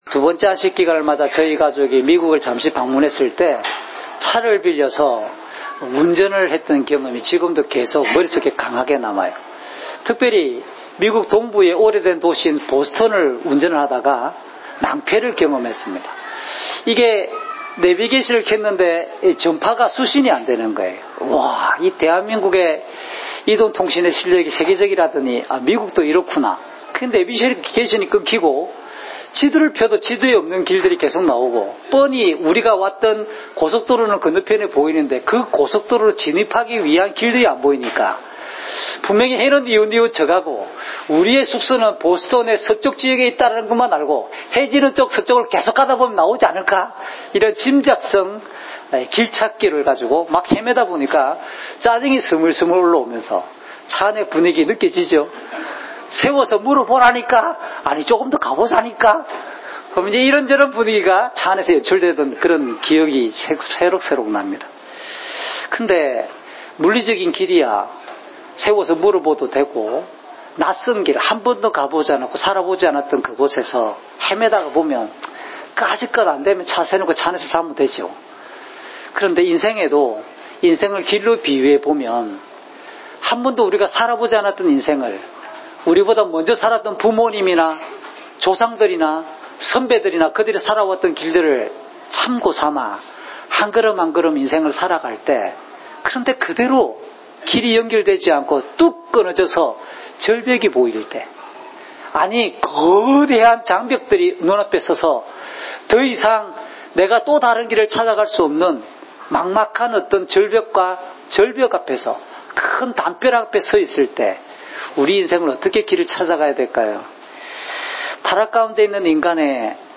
주일 목사님 설교를 올립니다.